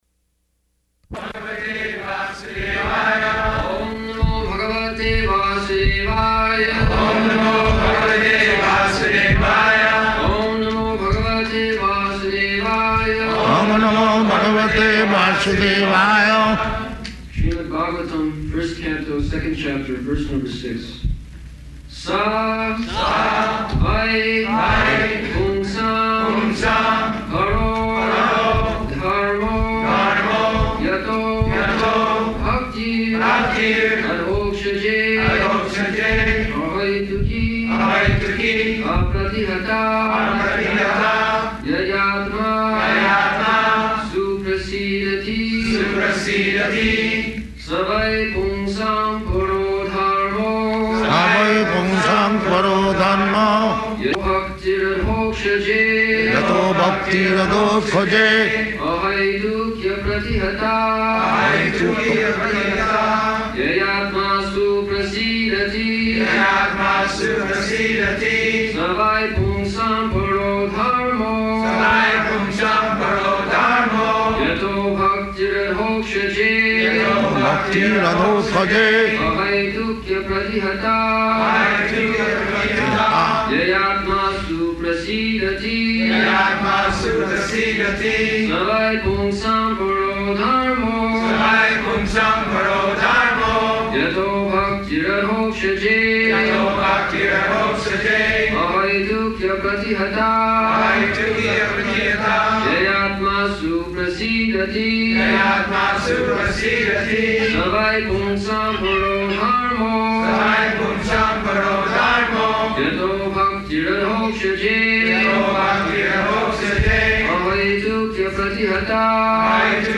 -- Type: Lectures and Addresses Dated